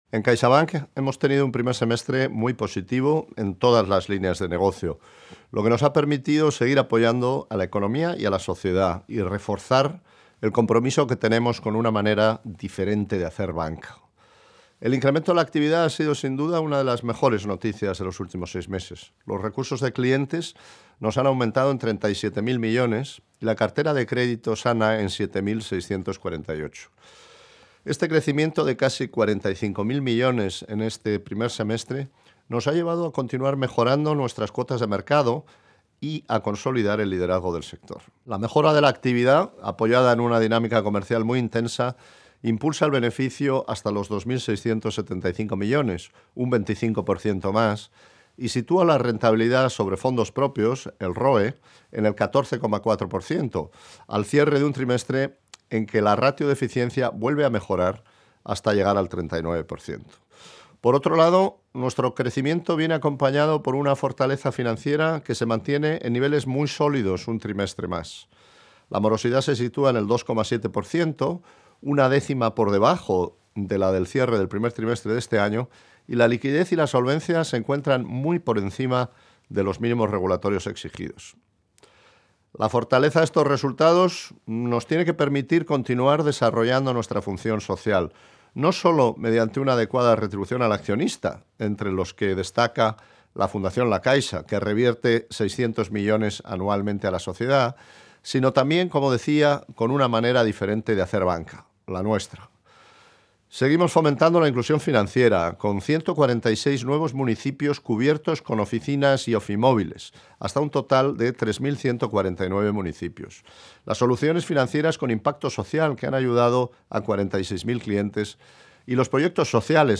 Audio del CEO de CaixaBank, Gonzalo Gortázar